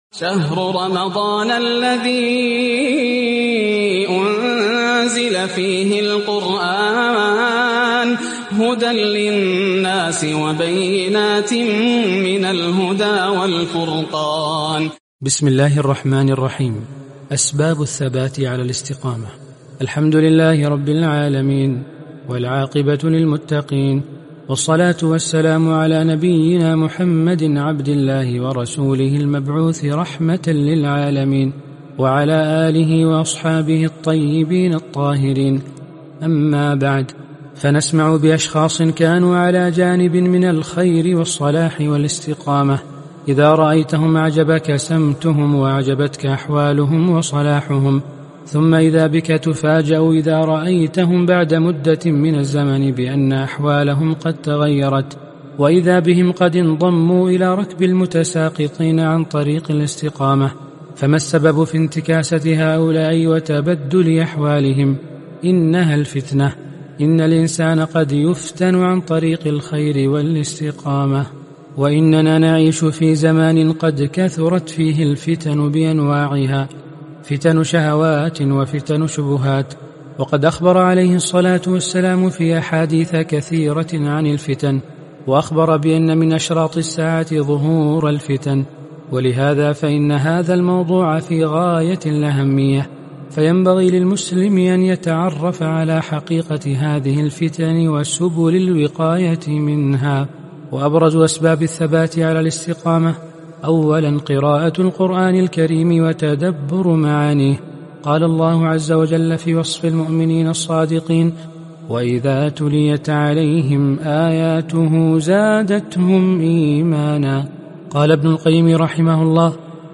عنوان المادة (35) القراءة الصوتية لكتاب عقود الجمان - (الدرس 34 أسباب الثبات على الاستقامة)